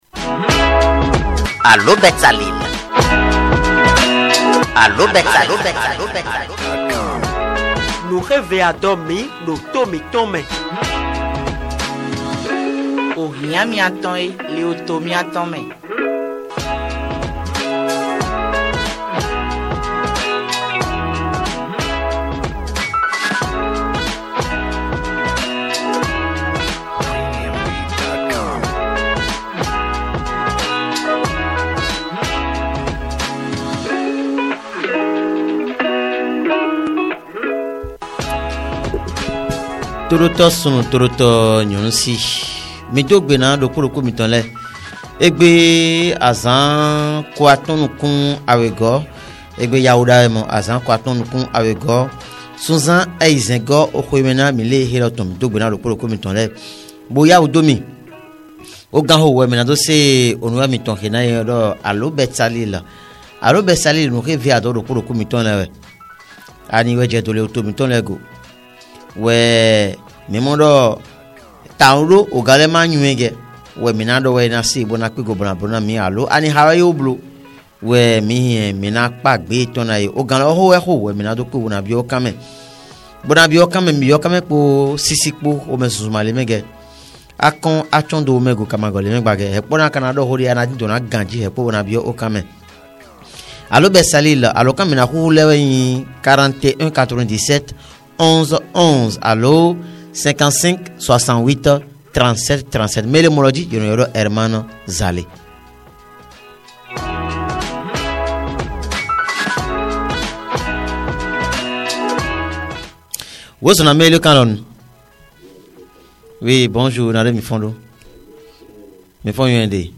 L'impraticabilité des voies, l'accès difficile de la population à l'eau potable, aux soins de santé et à l'électricité sont les préoccupations majeures évoquées par les auditeurs dans l'émission Allô Betsaleel de ce jeudi 27 juin 2024.